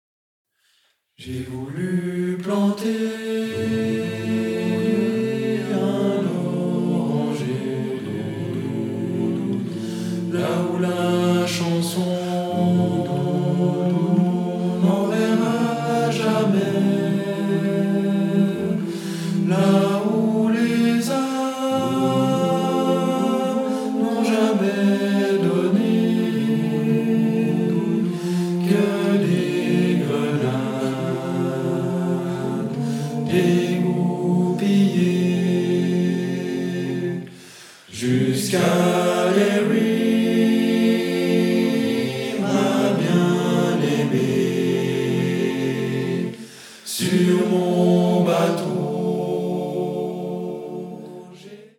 Octuor d’hommes